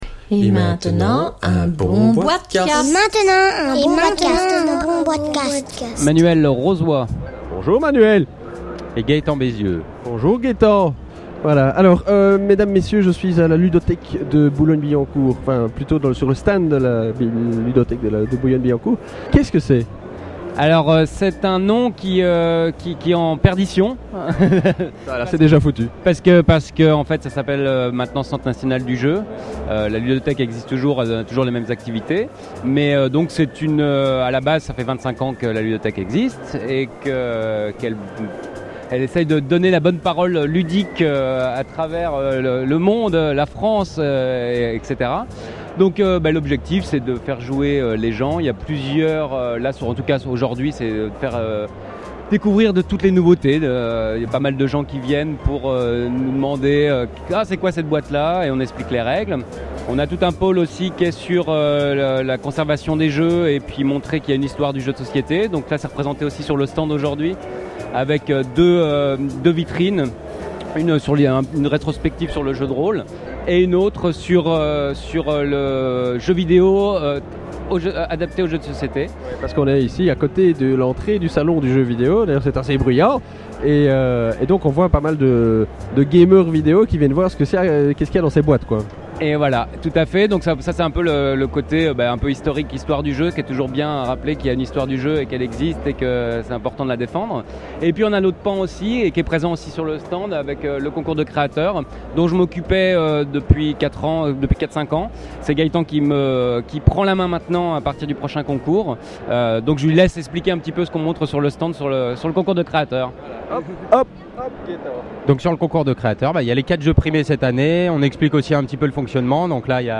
Enregistré au « Monde du jeu » le 27 Septembre – Paris